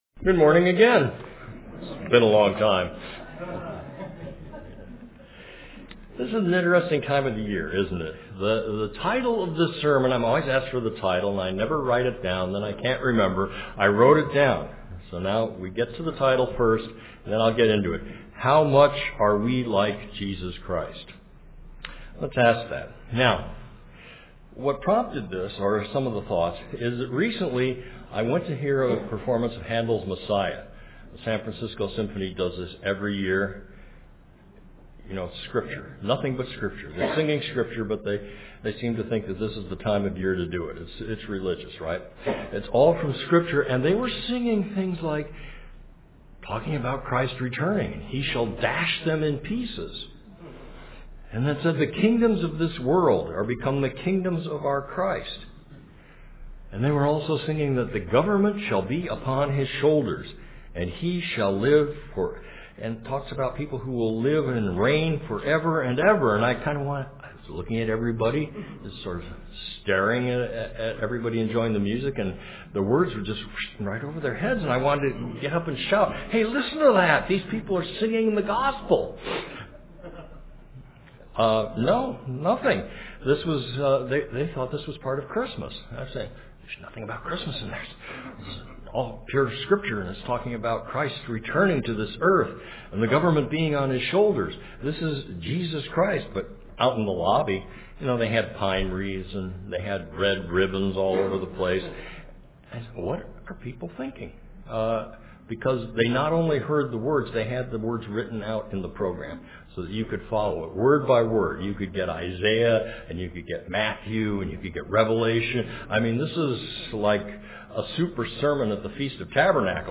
Given in San Francisco Bay Area, CA
UCG Sermon Studying the bible?